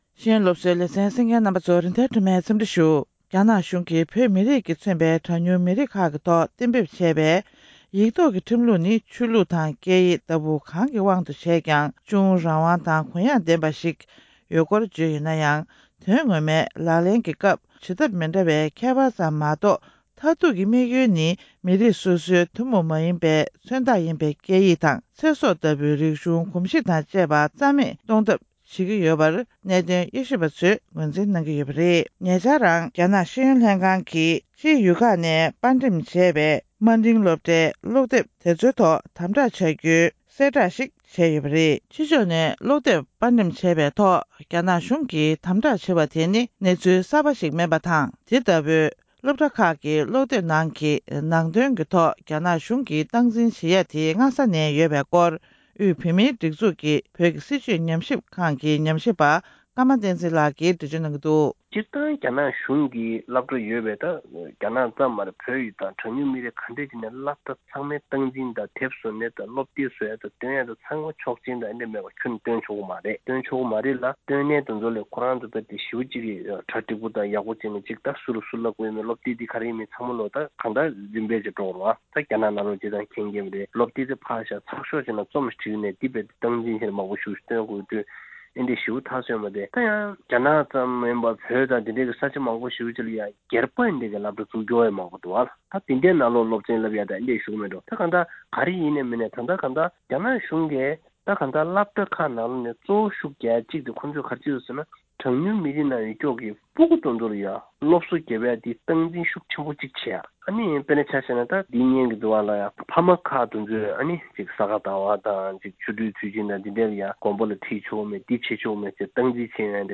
བོད་ཡུལ་རྒྱ་ཡུལ་དུ་འགྱུར་རྒྱུའི་ཐབས་ཤེས་བྱེད་བཞིན་པའི་སྲིད་བྱུས། སྒྲ་ལྡན་གསར་འགྱུར།